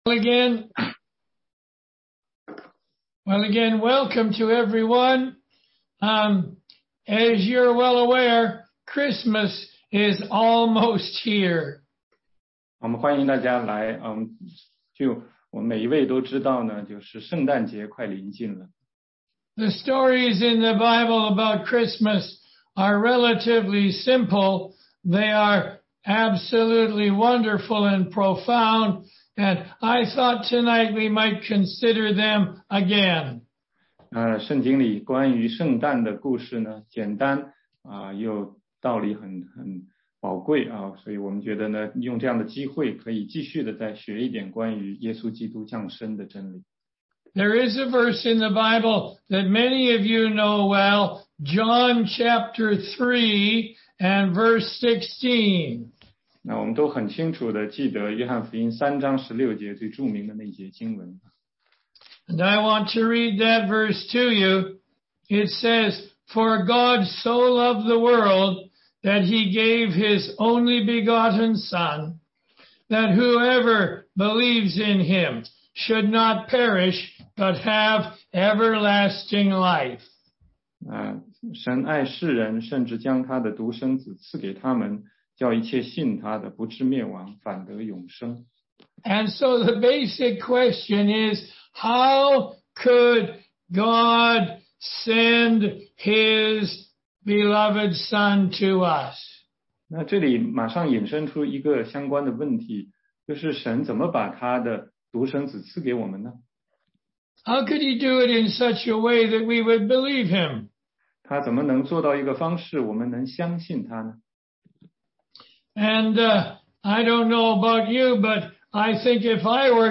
16街讲道录音 - 福音课第十讲